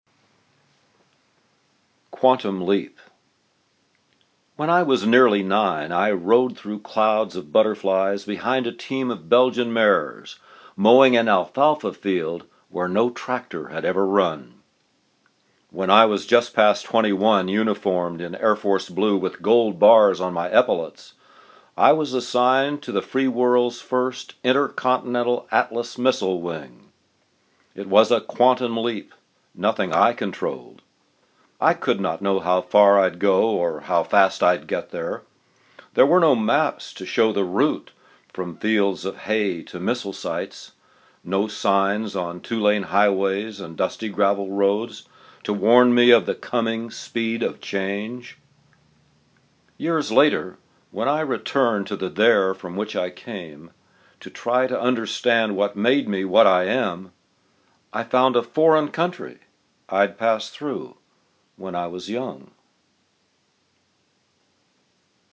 reading of this poem